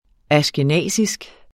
Udtale [ aɕgəˈnæˀsisg ]